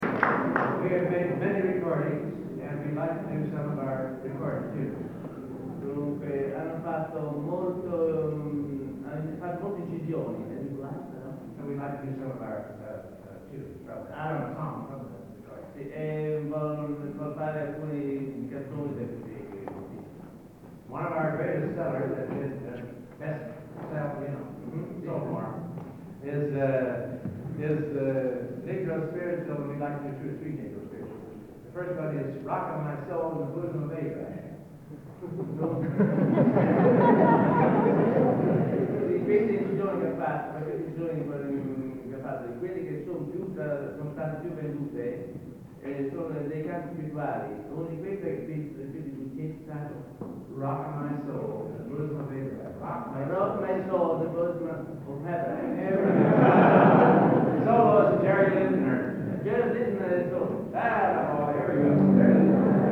Location: Florence, Italy
Genre: | Type: Director intros, emceeing